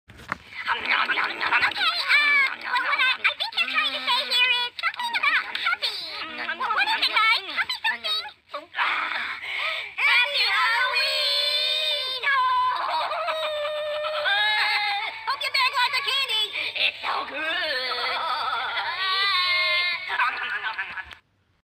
Hmmpfy Hallommpf! is a hoops&yoyo greeting card with sound made for halloween.